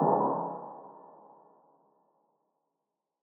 Metro Lumi Hit.wav